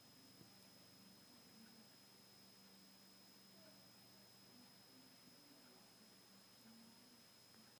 • ▲ ▼ Habe mal versucht das "Pfeifen" aufzunehmen.